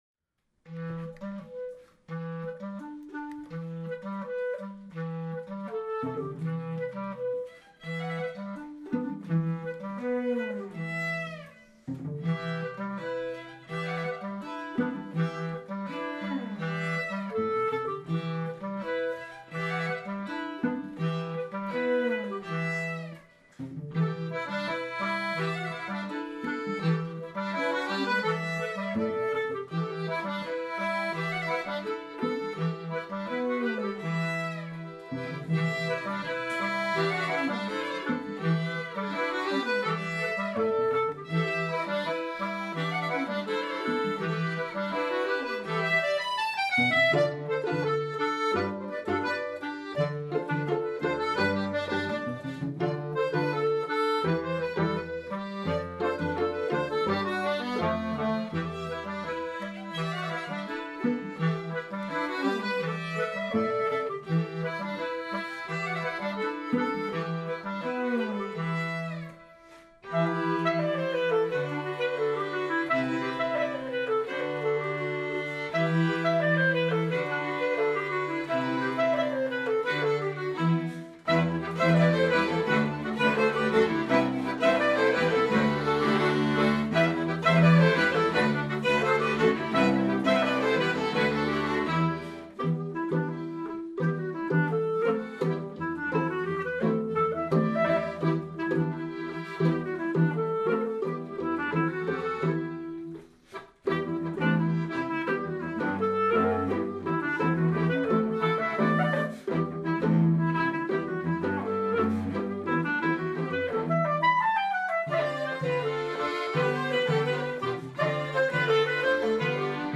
En concert ou en bal folk